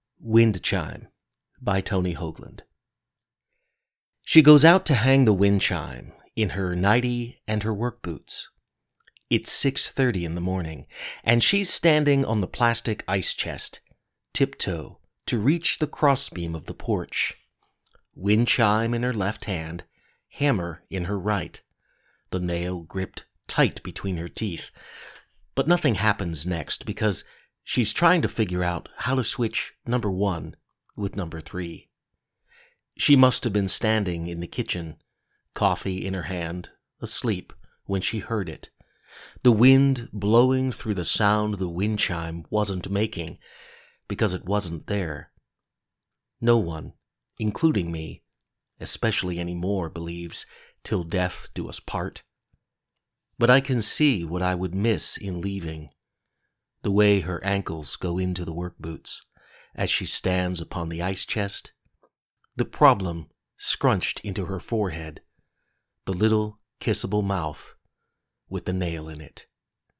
Below is my recording of the poem.